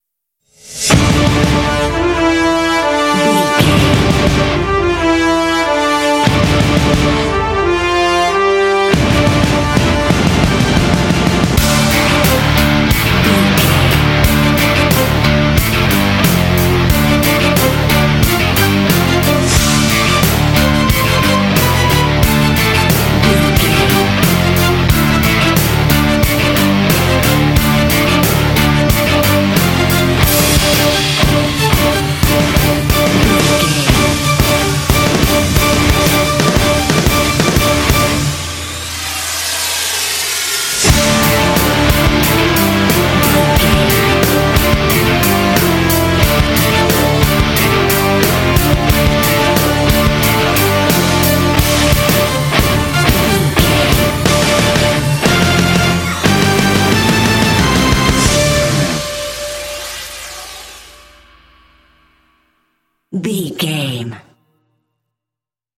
Aeolian/Minor
intense
driving
aggressive
drums
brass
electric guitar
bass guitar
strings
symphonic rock